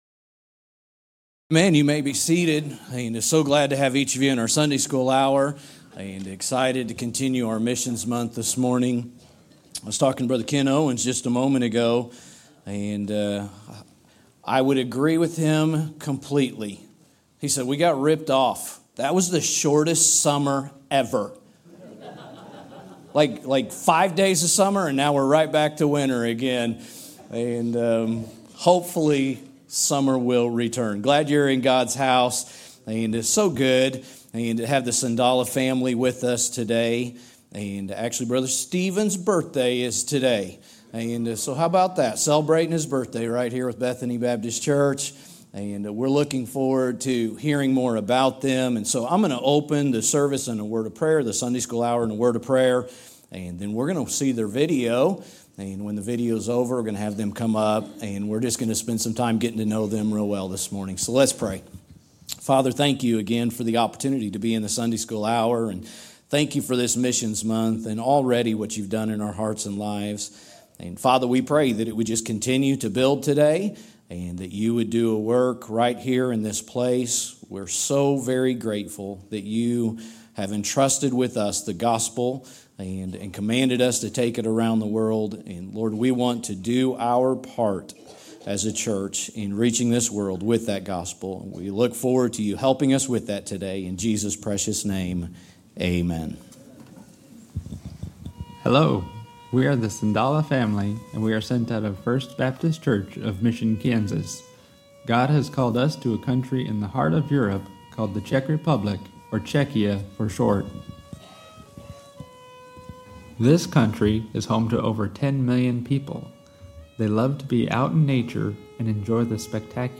A message from the series "2025 Missions Month."